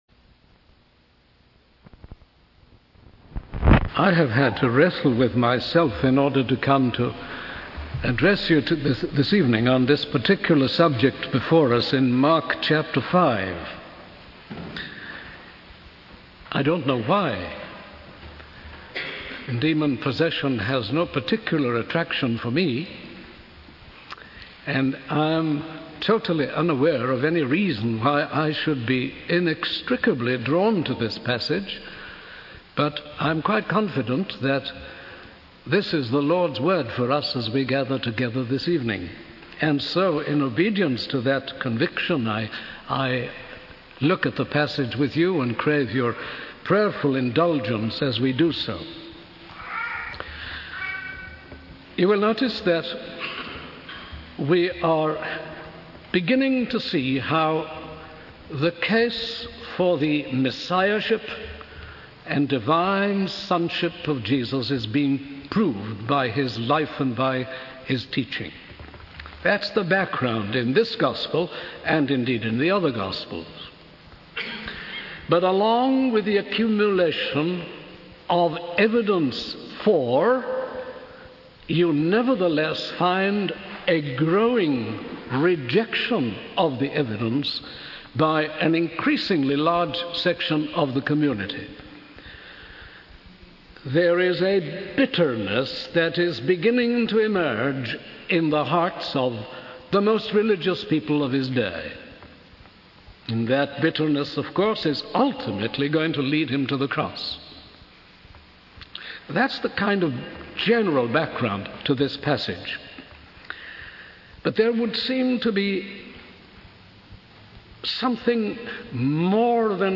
In this sermon, the preacher discusses the events leading up to the passage in Mark chapter 5 where Jesus calms the storm on the lake. He highlights the growing rejection of Jesus by religious leaders and the bitterness that ultimately leads to his crucifixion. The preacher suggests that chapter 5 aims to show Jesus' power and authority over demonic forces, following his display of power over natural forces in the previous chapter.